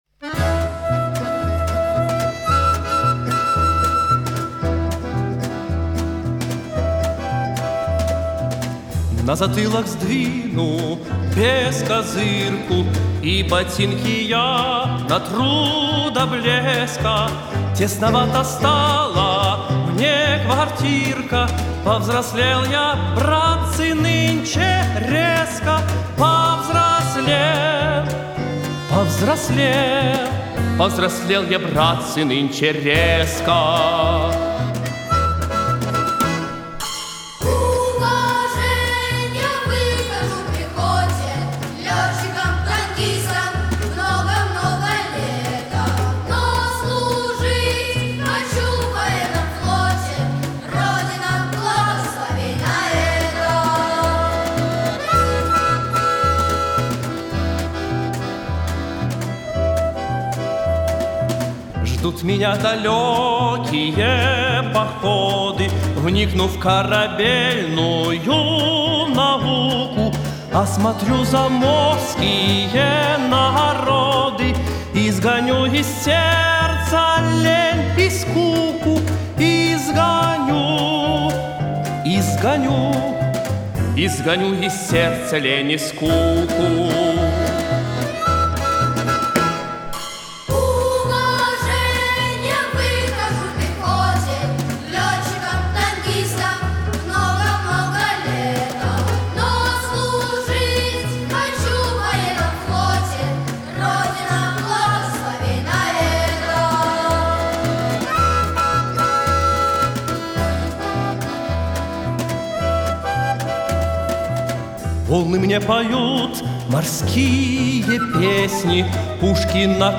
Исполняет хор мальчиков.